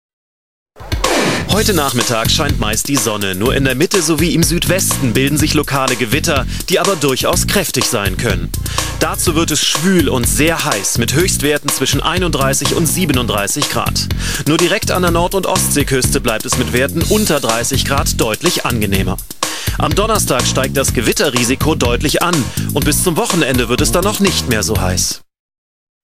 - exercices de compréhension auditive à partir de bulletins météos. (fiche).